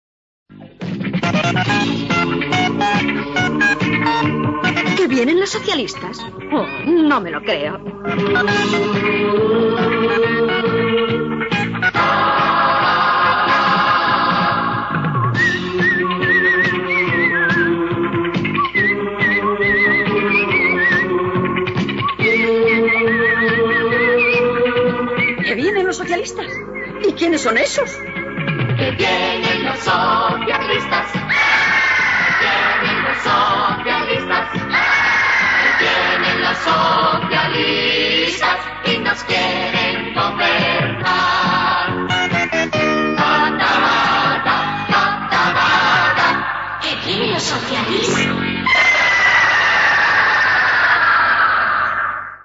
No vos perdeu la Banda Sonora: